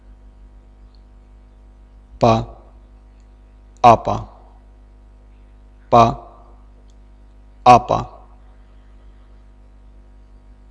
Consonnes - Sujet #4
p_pa_apa4_[16b].wav